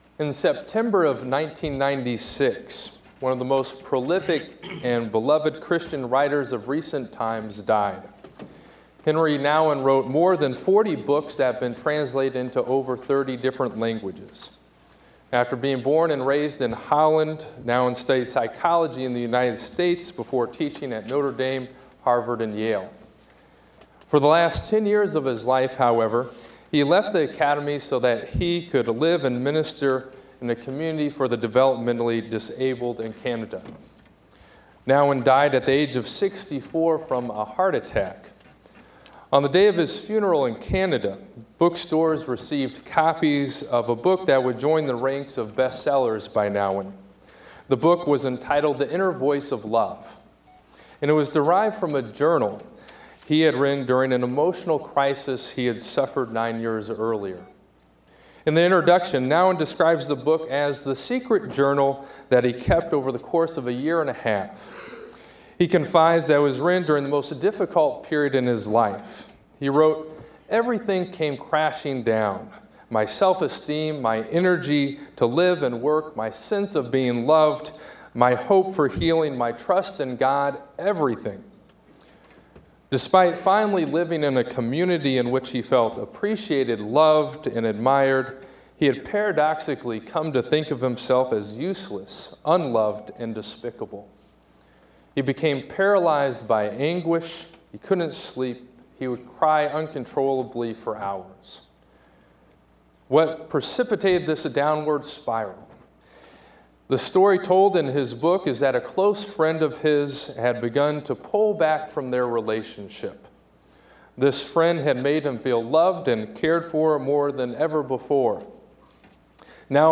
Scripture Reading—Two Translations of Hebrews 11:1